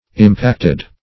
Impacted \Im*pact"ed\, a.